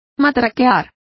Complete with pronunciation of the translation of rattled.